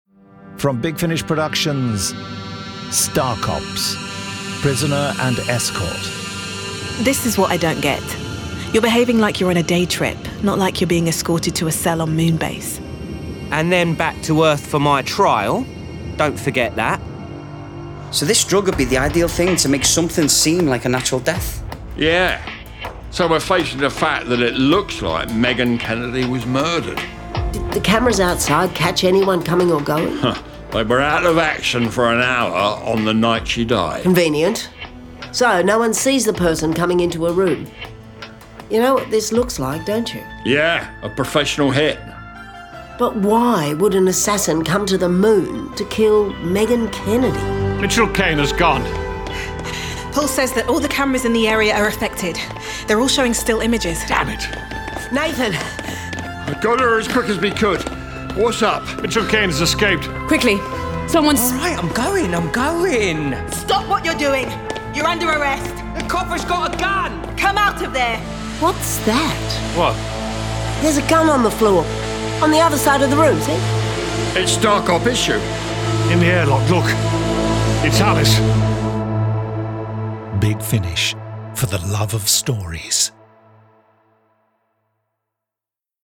Award-winning, full-cast original audio dramas from the worlds of Doctor Who, Torchwood, Blake's 7, Class, Dark Shadows, Avengers, Omega Factor, Star Cops, Sherlock Holmes, Dorian Gray, Pathfinder Legends, Prisoner, Adam Adamant Lives, Space 1999, Timeslip, Terrahawks, Space Precinct, Thunderbirds, Stingray, Robin Hood, Dark Season, UFO, Stargate, V UK, Time Tunnel, Sky, Zygon Century, Planet Krynoid, Turpin, Young Bond